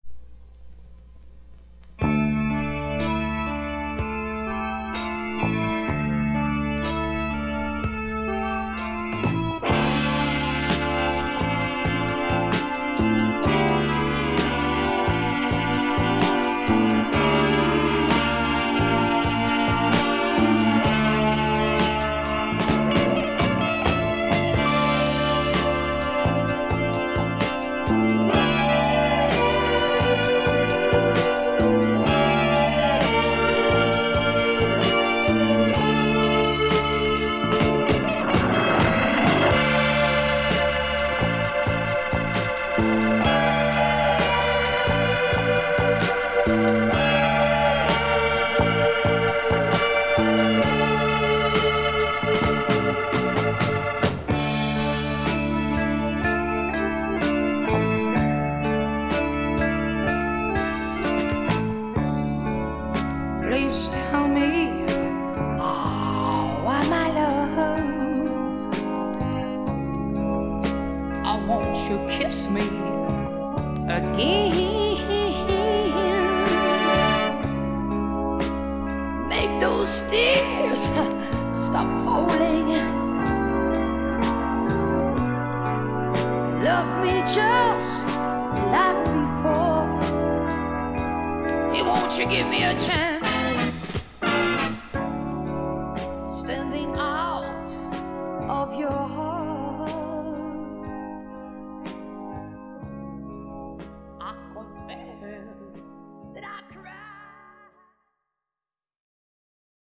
Recorded: Olympic Sound Studio in Barnes / London, England